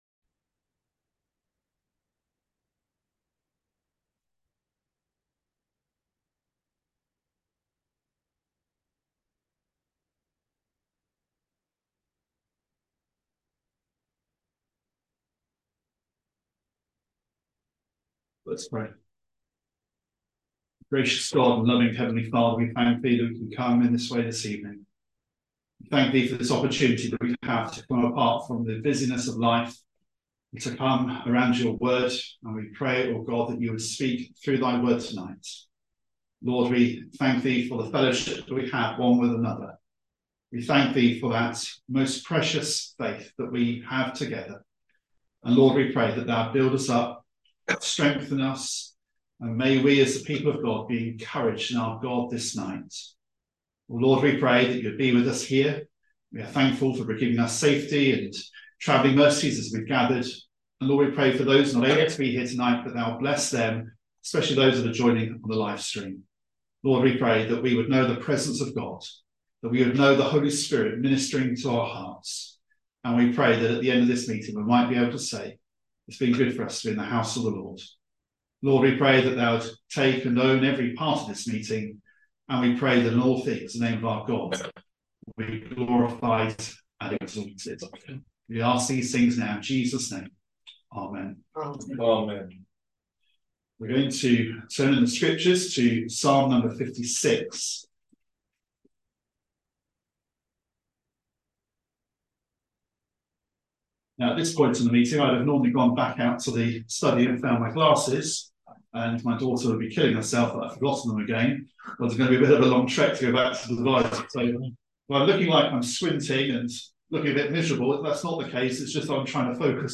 Passage: Psalm 56:1-4 Service Type: Wednesday Bible Study Trusting in the Lord « Sunday Evening